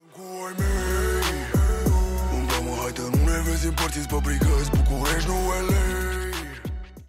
This sound is perfect for adding humor, surprise, or dramatic timing to your content.